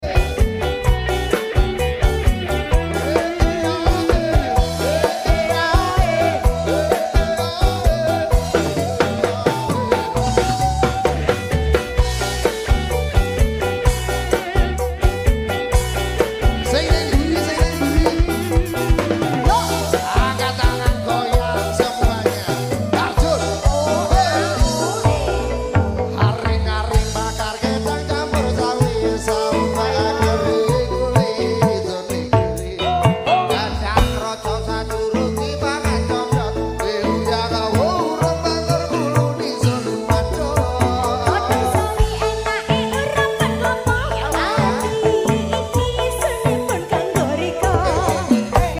KOLABORASI MUSISI, VOCALIS, DAN SOUND SYSTEM TERKEREN LIVE